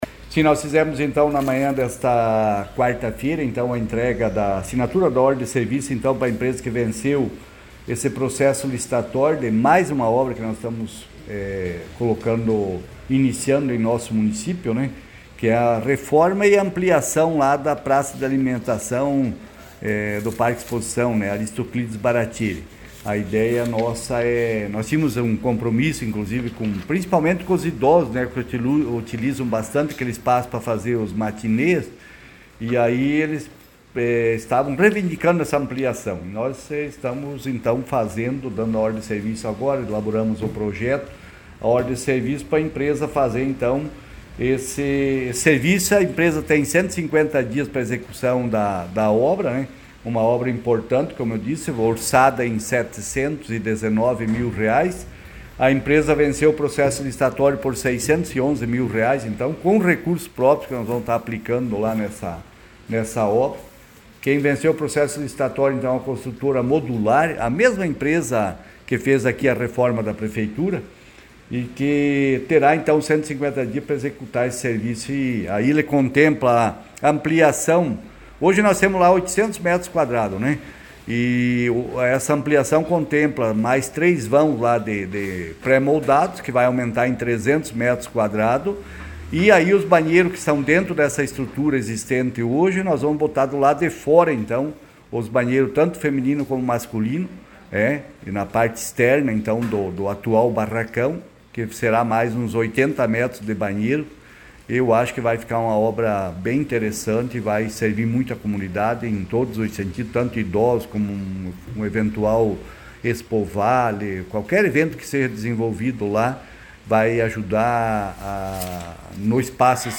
Prefeito_de_Capinzal,_Nilvo_Dorini.mp3